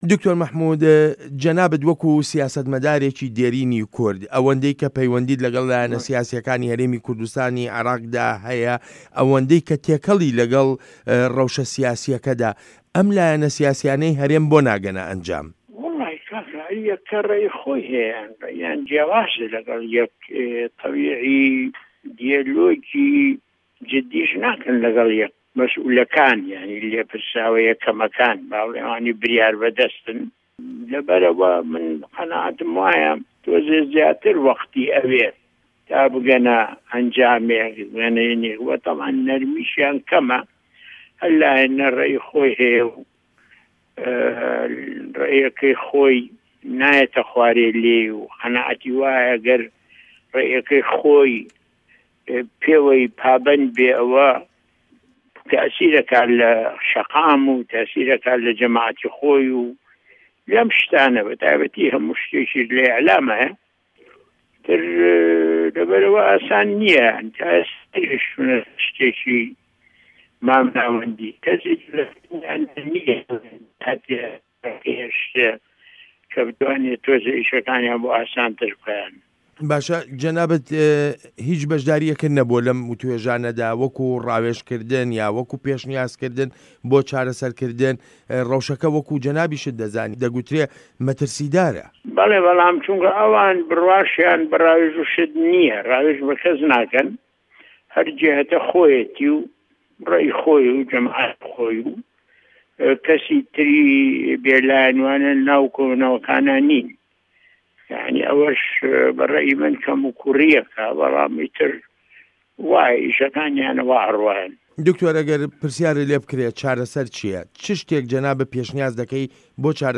وتووێژ لەگەڵ دکتۆر مەحمود عوسمان